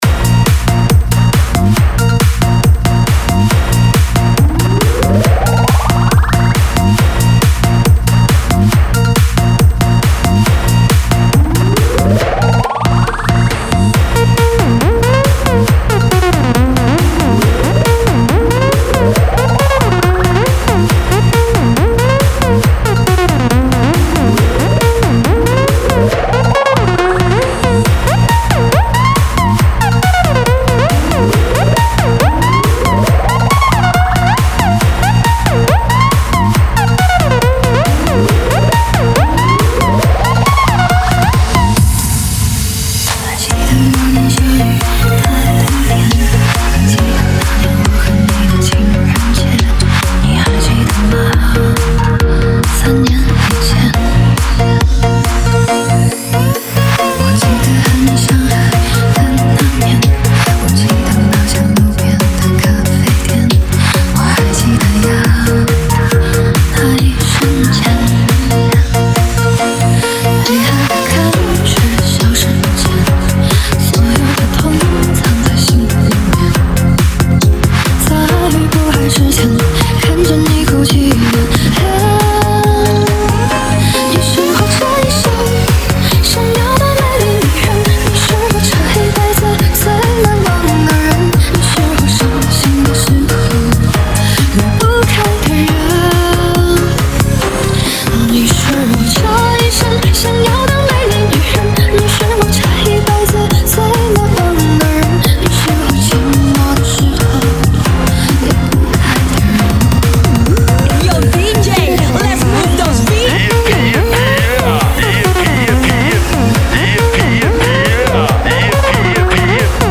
4天前 DJ音乐工程 · VinaHouse 2 推广